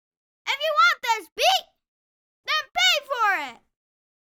PayForIt (KidVoice).wav